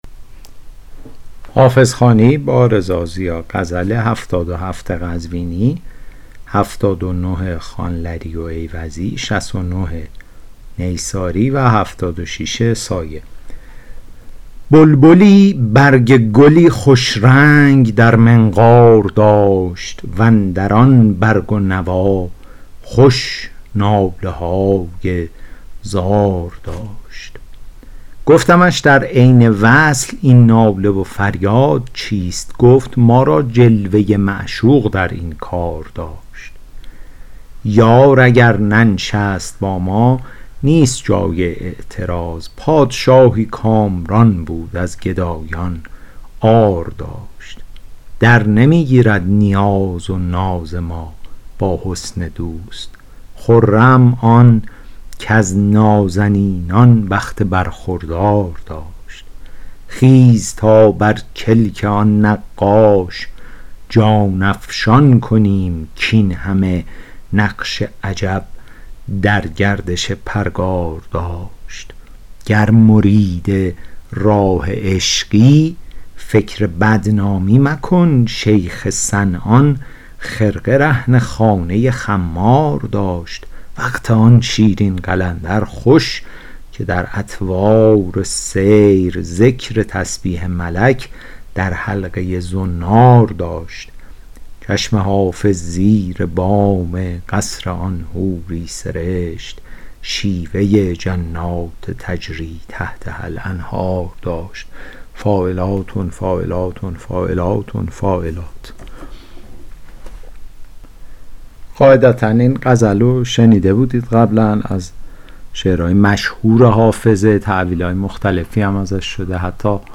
شرح صوتی غزل شمارهٔ ۷۷